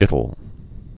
(ĭtl)